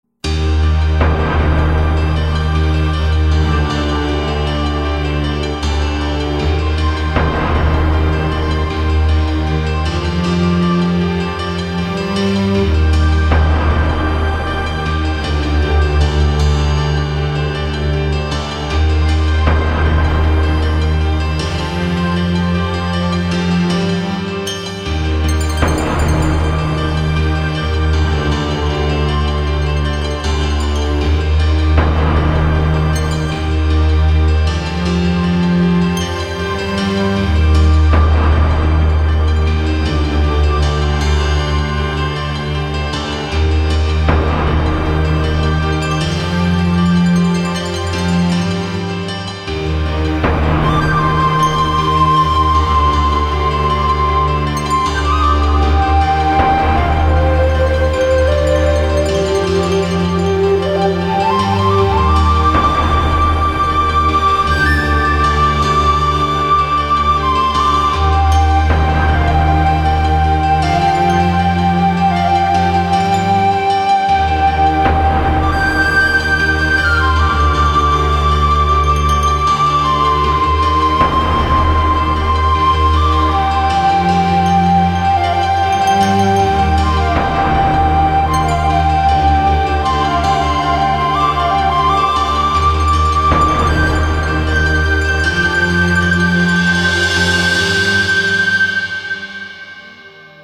其配乐精湛若斯，音符跌宕，随着历史叙述的起伏不断变换着节奏，低沉的打击乐仿佛敲响了永乐朝的大钟